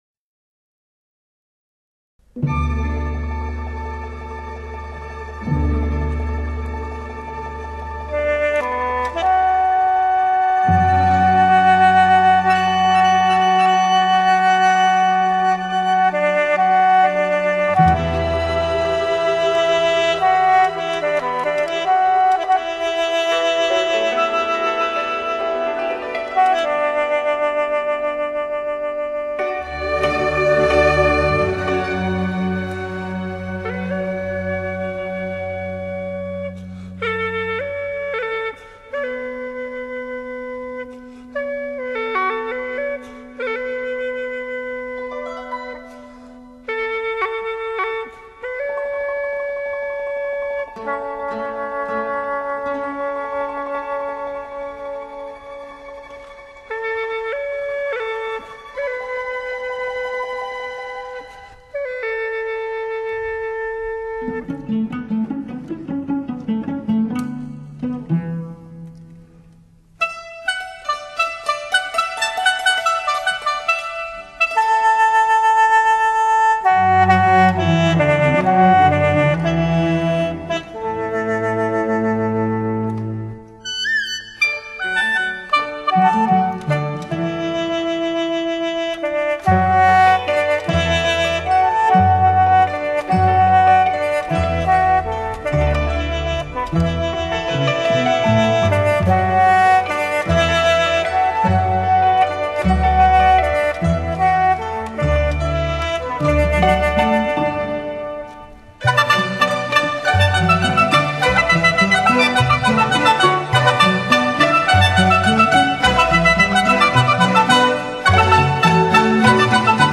由于发音原理的不同，其种类和音色极为丰富多彩，个性极强。
迂回婉转的音律，卓越深厚的演奏、憾人肺腑的乐魂、